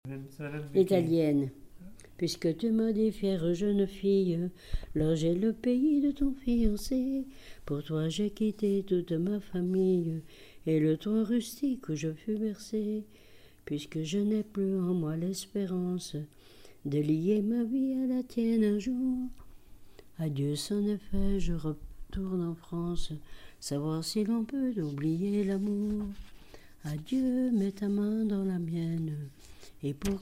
Genre laisse
chansons et témoignages
Pièce musicale inédite